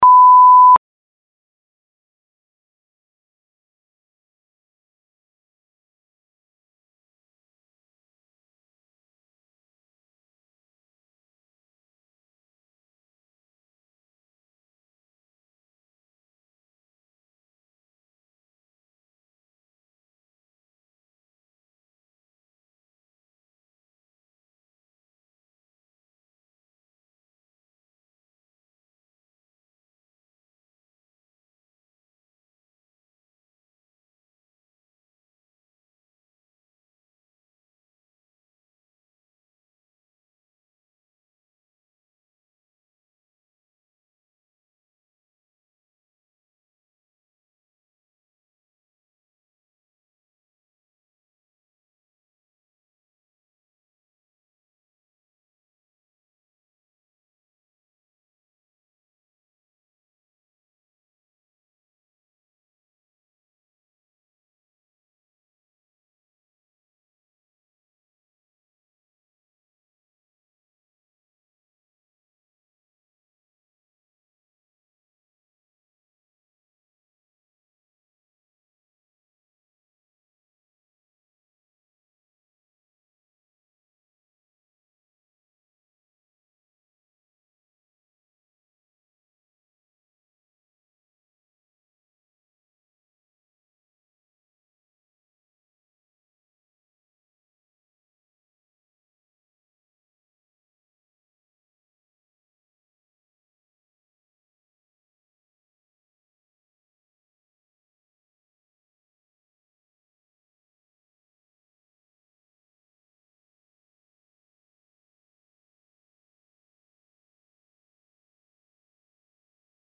5 Minute Interval Beep
beep.mp3